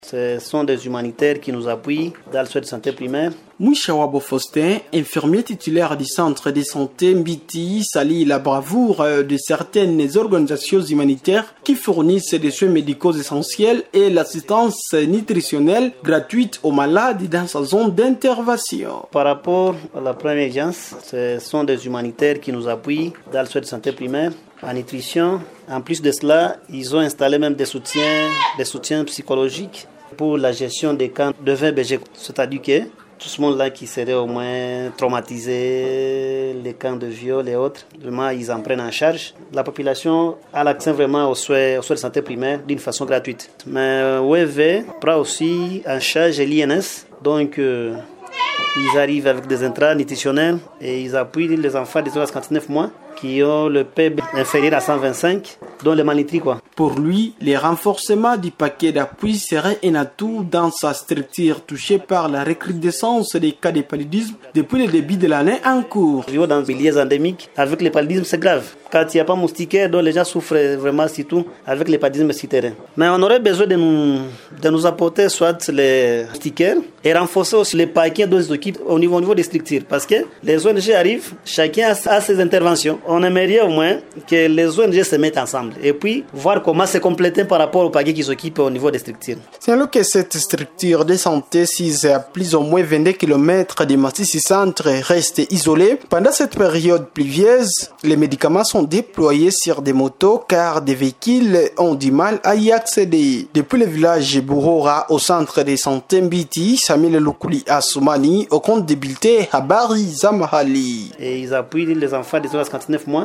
REPORTAGE-SUR-LE-ROLE-DES-ONG-FR.mp3